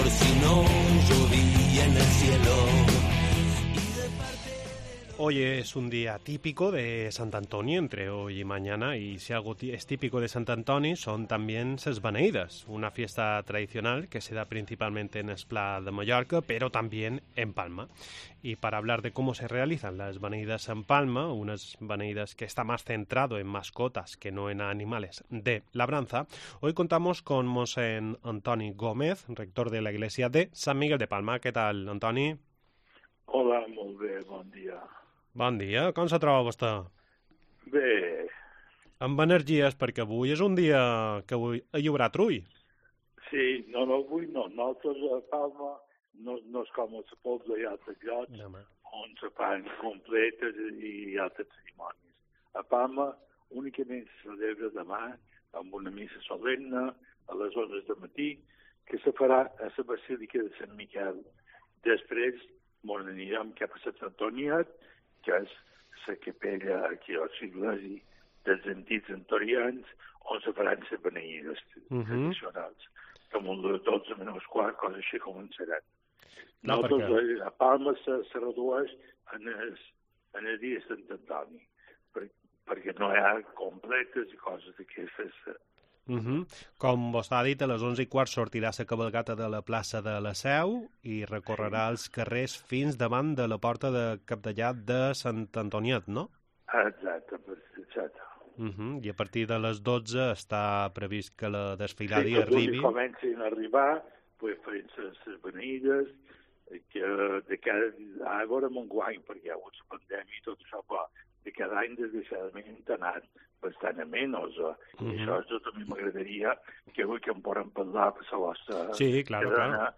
Comenzamos el programa charlando